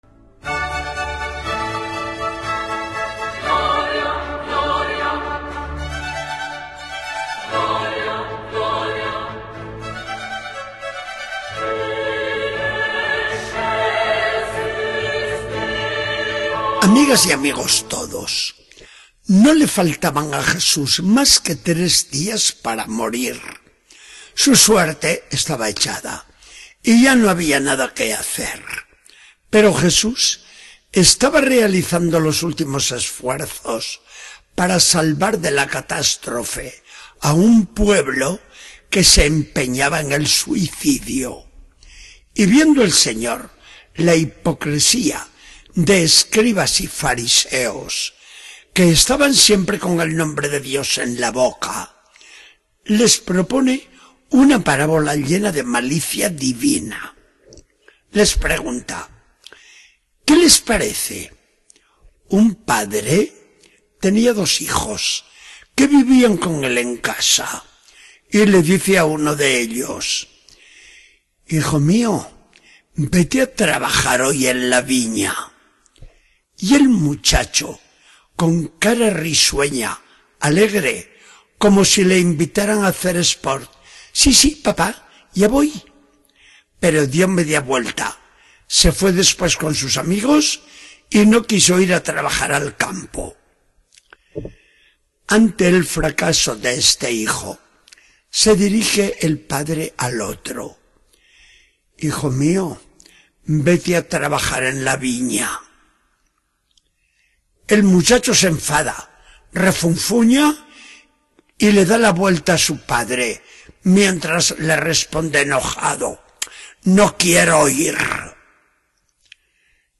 Charla del día 28 de septiembre de 2014. Del Evangelio según San Mateo 21, 28-32.